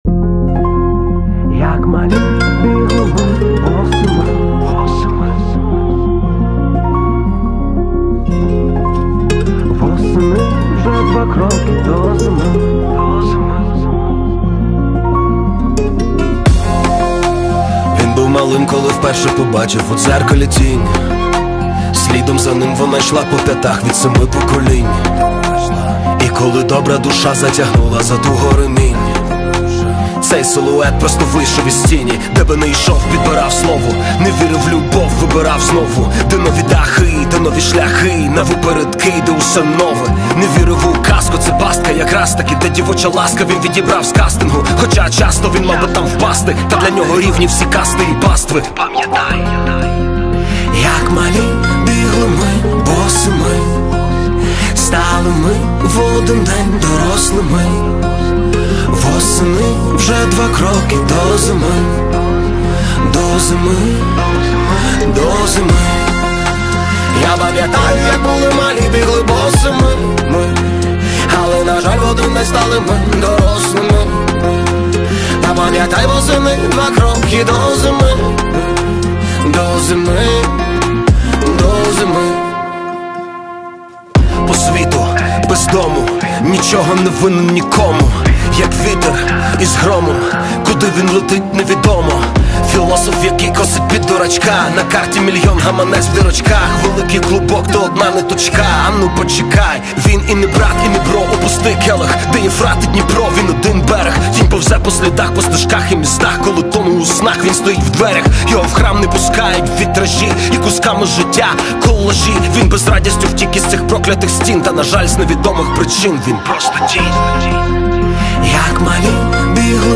Реп / хіп-хоп